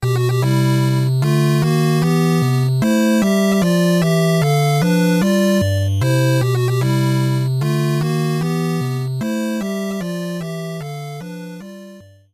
Converted to ogg and added a fade-out.